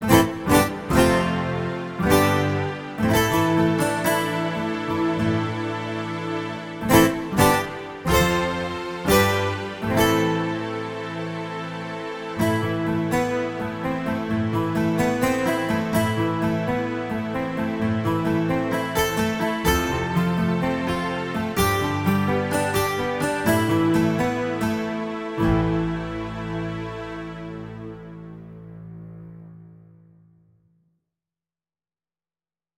This is a collection of 3 Program sounds powered by the Multisample called “Guitars” which is a detailed stereo sampled sound of a group of 12 String Guitars in a stereo mix.